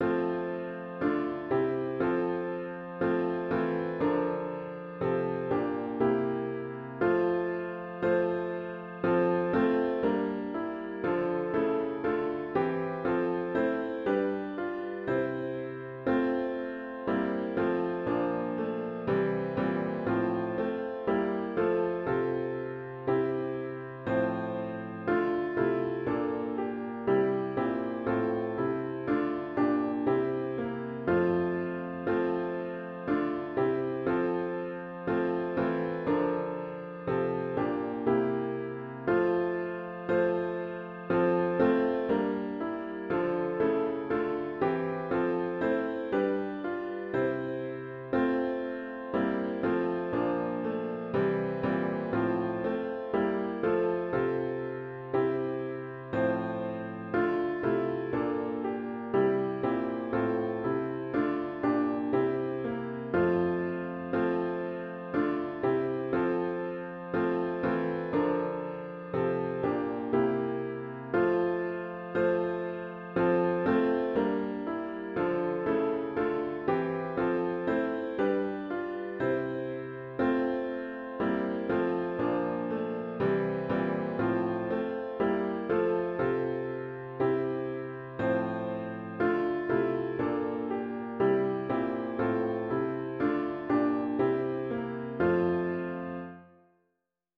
CLOSING HYMN “Lord, We Have Come at Your Own Invitation” GtG 503